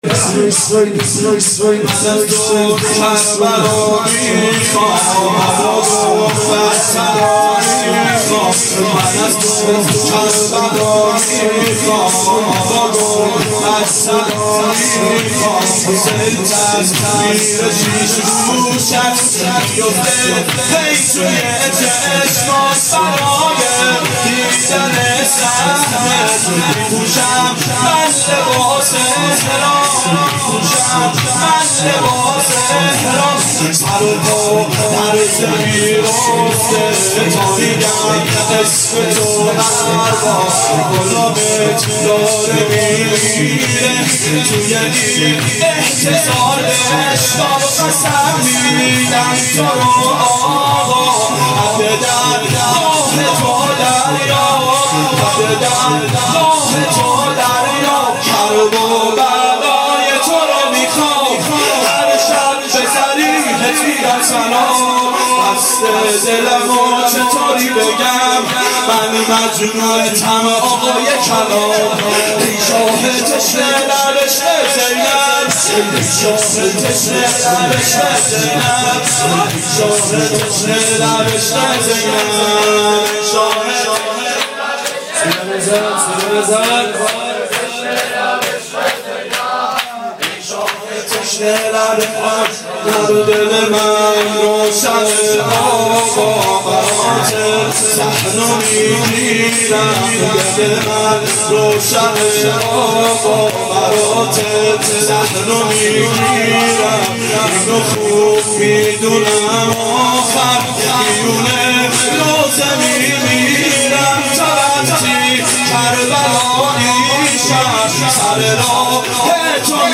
ظهر اربعین سال 1390 محفل شیفتگان حضرت رقیه سلام الله علیها